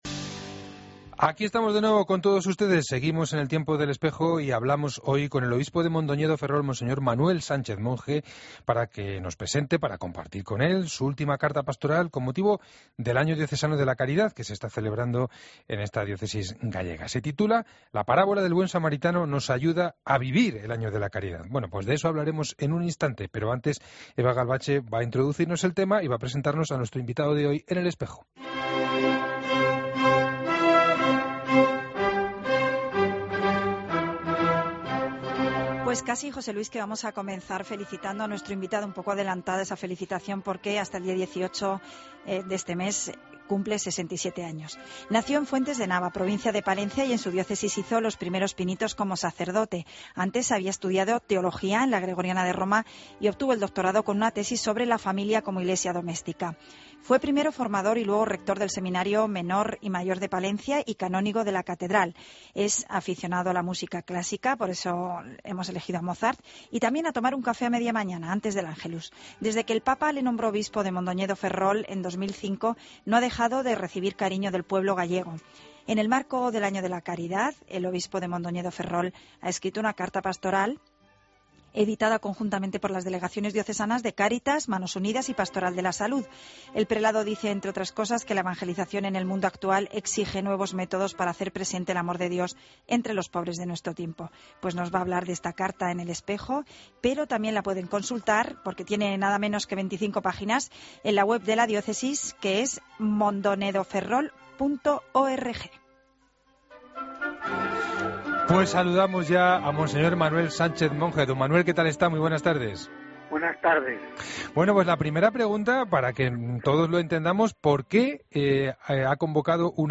AUDIO: Escucha la entrevista completa a monseñor Manuel Sánchez Monge en 'El Espejo' de COPE